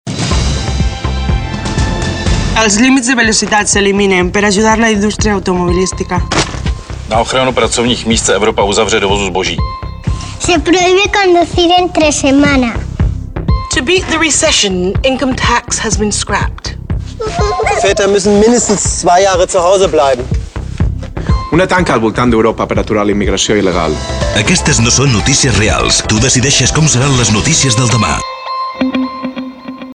catalana (àudio), al text no se li ha modificat ni una coma; només s'han canviat els actors, que diuen el mateix però amb accent diferent.
valencia.mp3